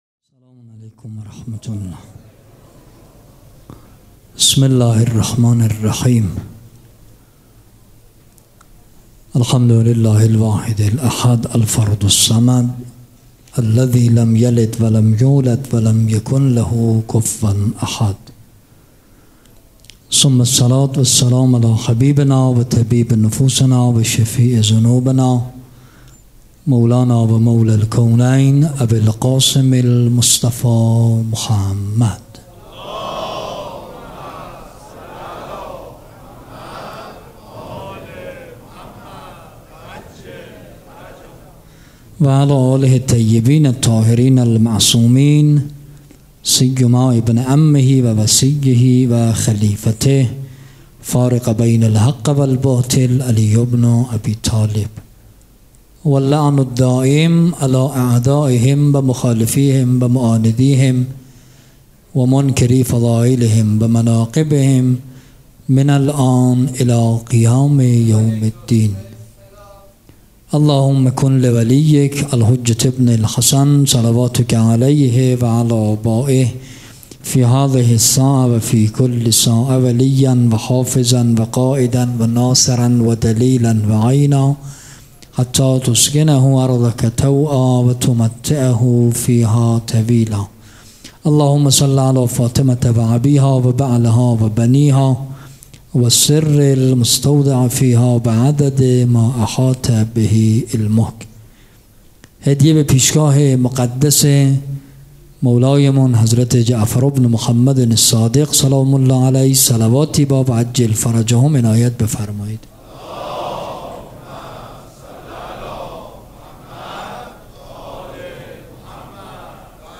سخنرانی
شهادت امام صادق علیه السلام سه شنبه 27 خرداد ماه 1399 حسینیه ی ریحانه الحسین (س)
سخنرانی.mp3